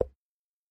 General Click.mp3